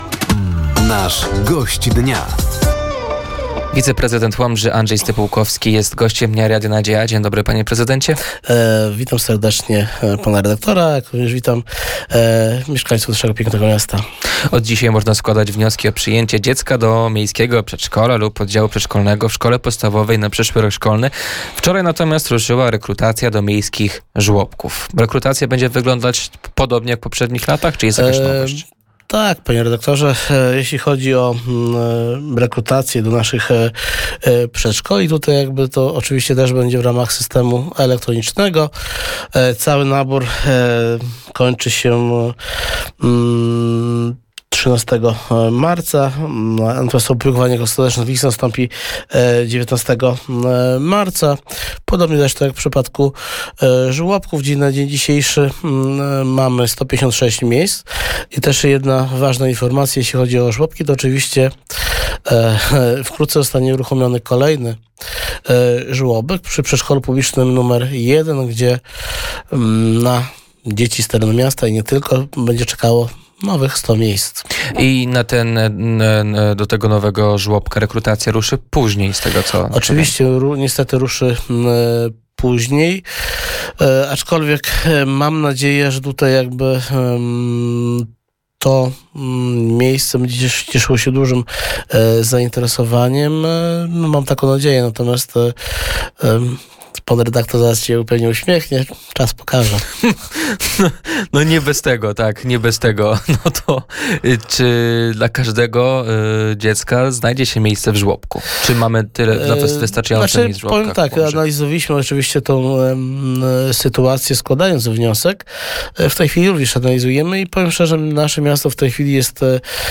Gościem Dnia Radia Nadzieja był wiceprezydent Łomży Andrzej Stypułkowski. Tematem rozmowy była rekrutacja do miejskich przedszkoli i żłobków, sytuacja demograficzna i ewentualne zmiany w oświacie.